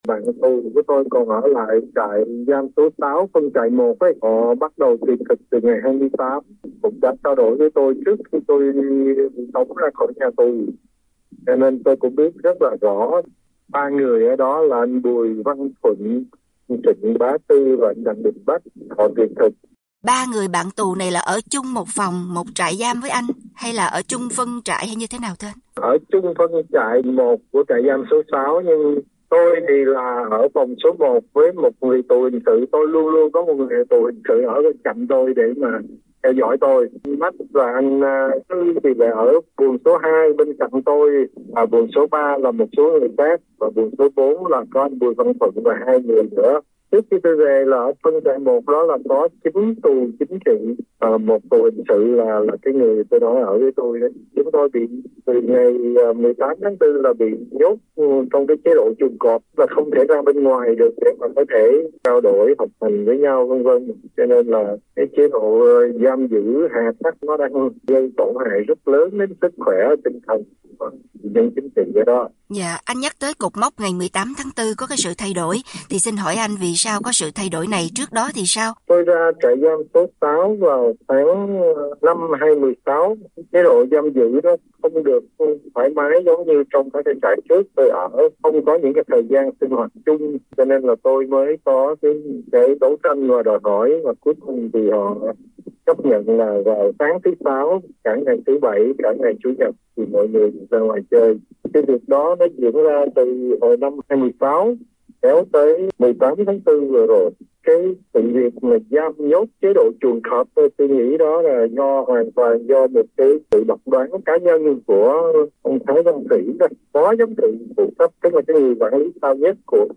trò chuyện